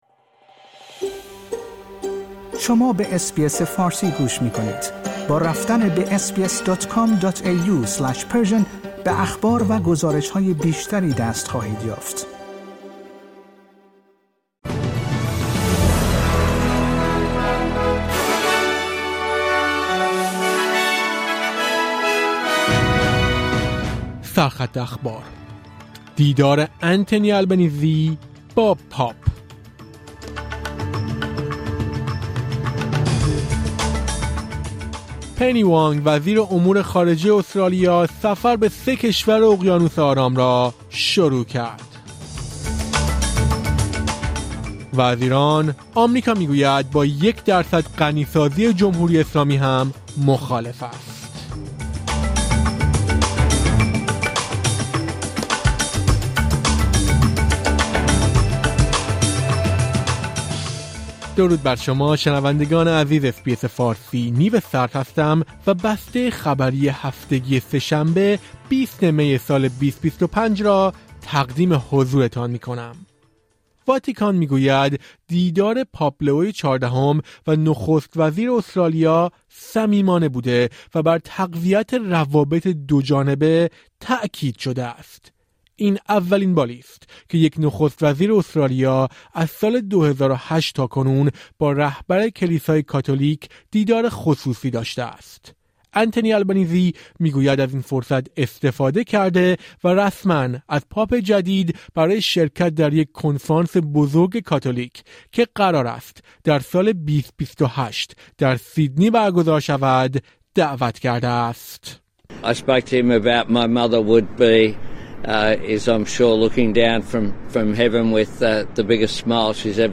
در این پادکست خبری مهمترین اخبار هفته منتهی به سه‌شنبه ۲۰ می آمده است.